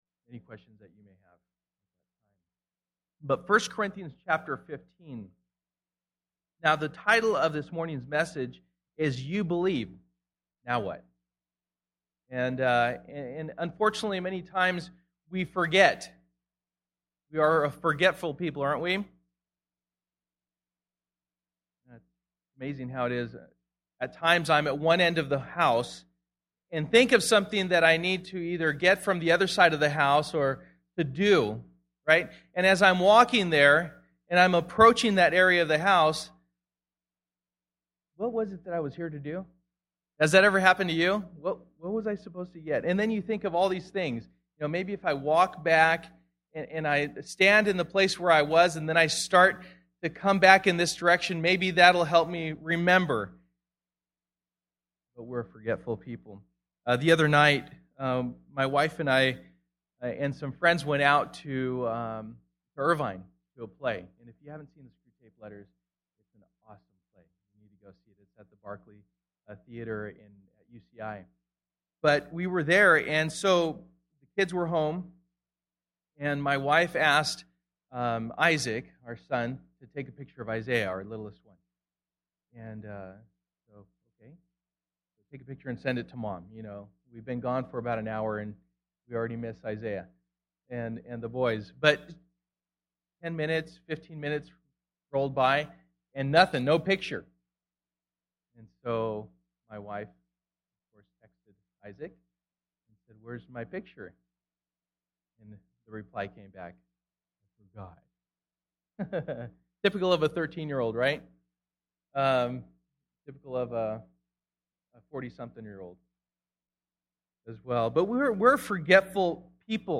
Sold Out Passage: 1 Corinthians 15:1-11 Service: Sunday Morning %todo_render% « Sold Out